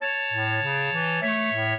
clarinet
minuet10-12.wav